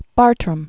(bärtrəm), John 1699-1777.